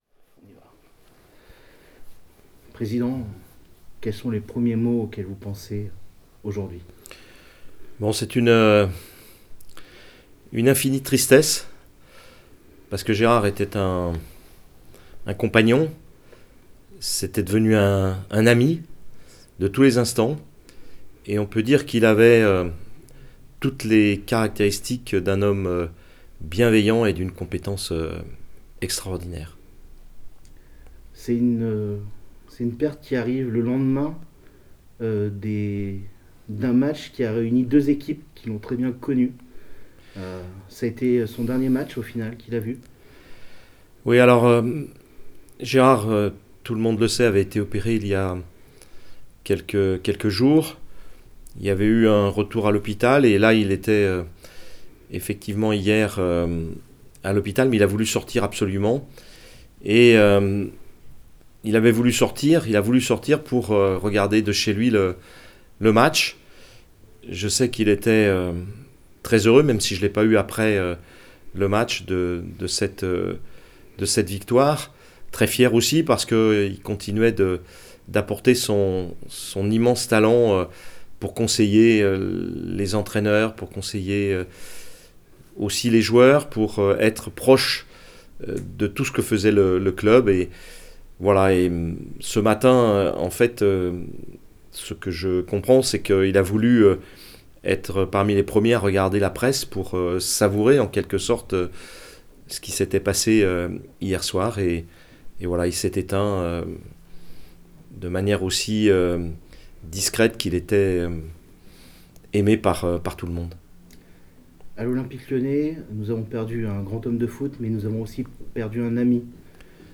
La réaction de Jean-Michel Aulas :
REACTION-PRESIDENT-AULAS-GERARD-HOUILLER.wav